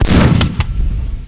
sound / soldier / sattck1.wav
1 channel